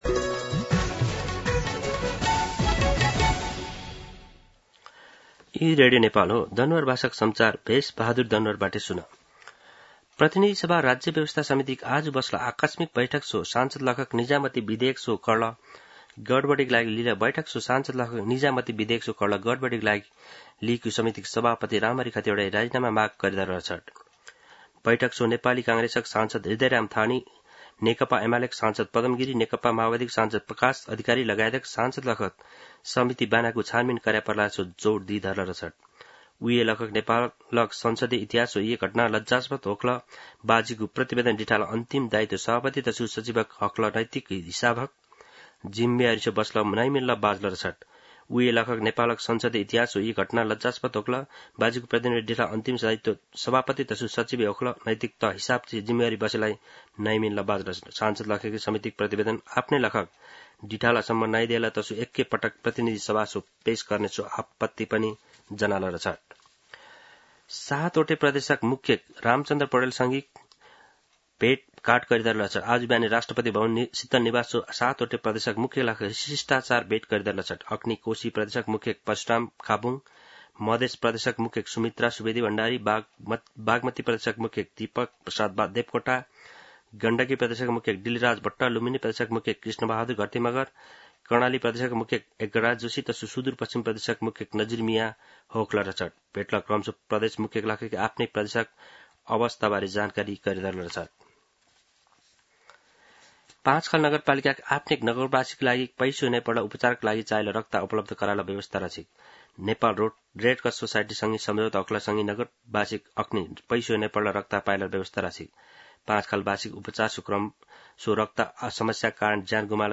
दनुवार भाषामा समाचार : १८ असार , २०८२
Danuwar-News-3-18-1.mp3